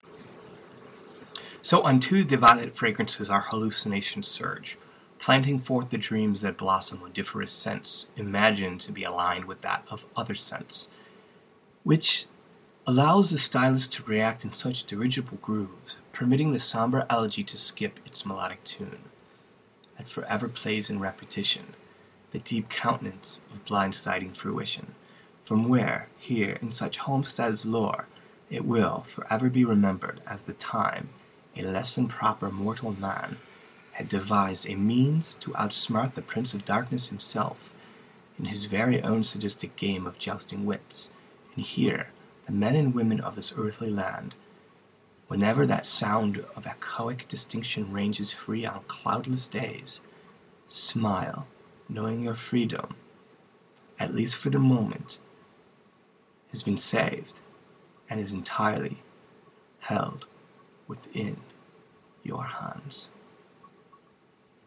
Myth, storytelling, metaphor, reading, recording, prose poetry, saga, journey, quest